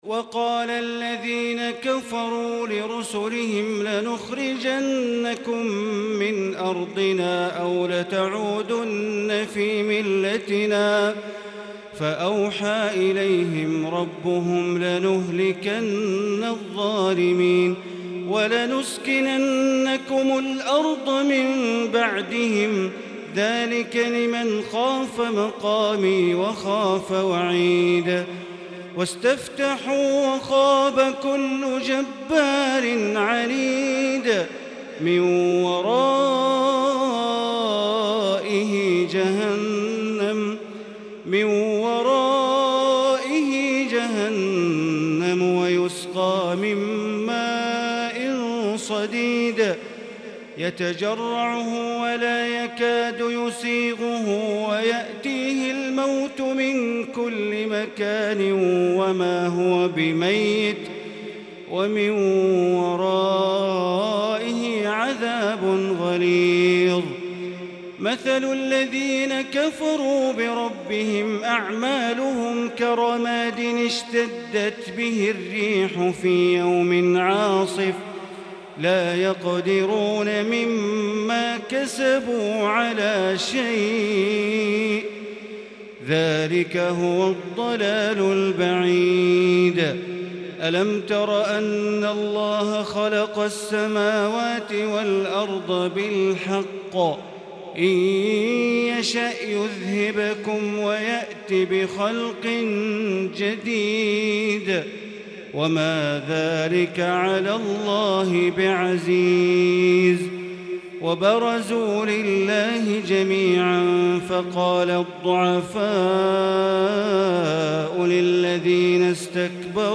تراويح ليلة 18 رمضان 1441هـ من سورة إبراهيم (13)إلى الحجر (79) Taraweeh 18 th night Ramadan 1441H > تراويح الحرم المكي عام 1441 🕋 > التراويح - تلاوات الحرمين